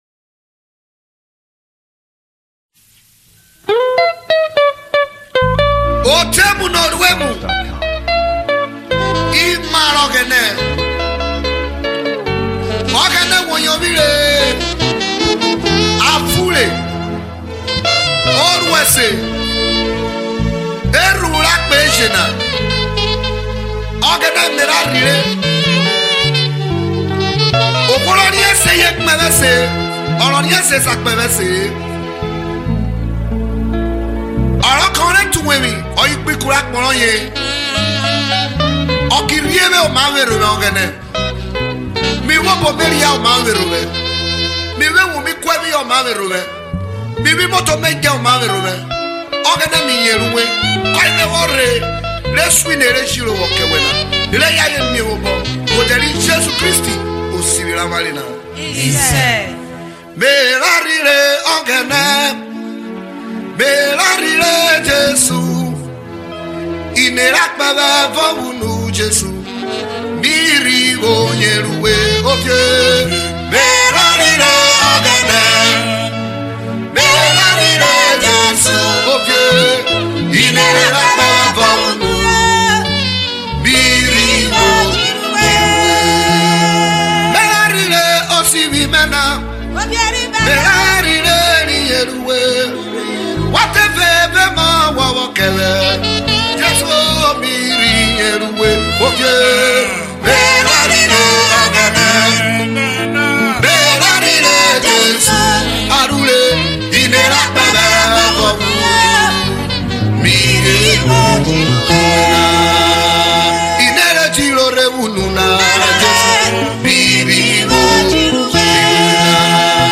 Gospel
Isoko